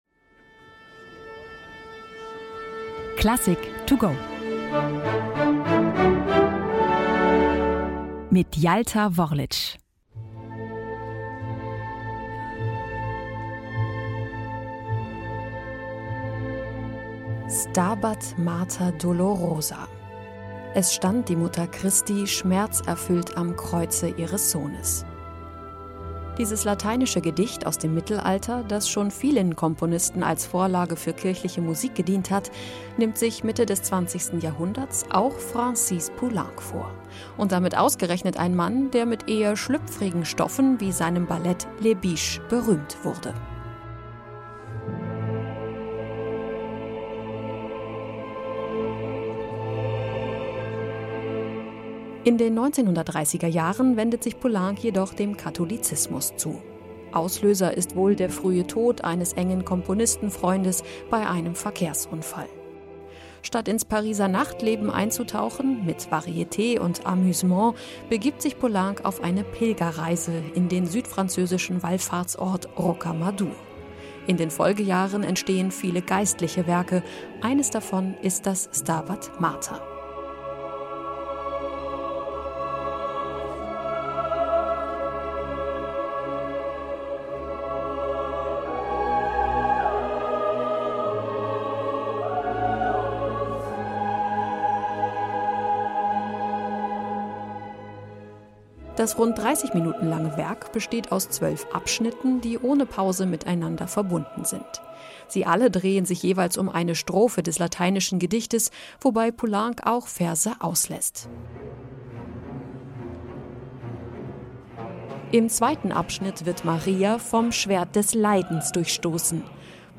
Werkeinführung für unterwegs: kurz und knapp noch vor Konzertbeginn